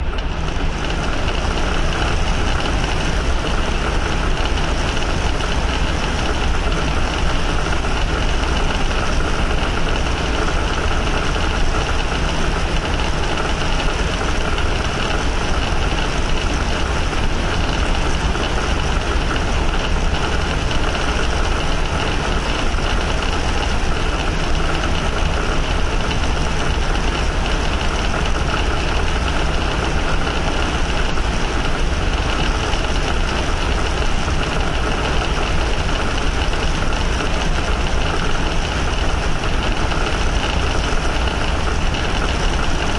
现场录音的水柜冲洗声音设计返工数第二
使用的技术：FFT、颗粒合成、物理建模、卷积、声码
声道立体声